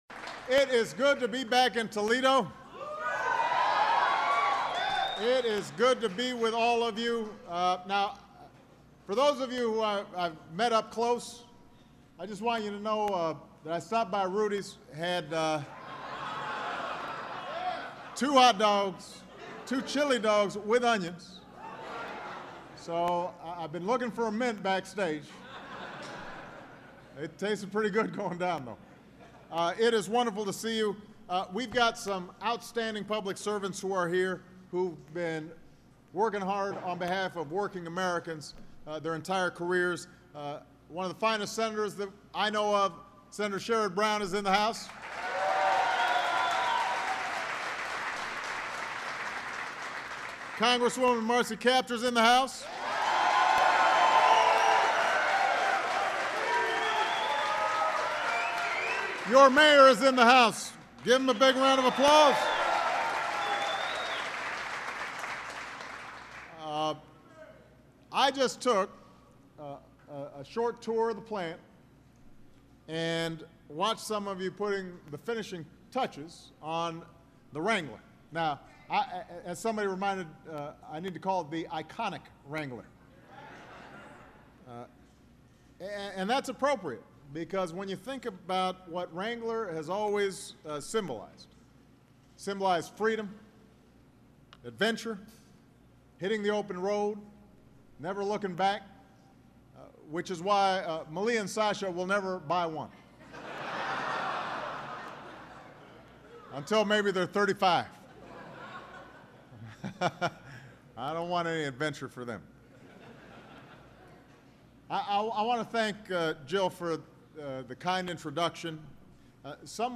U.S. President Barack Obama speaks to Chrysler workers in Toledo, Ohio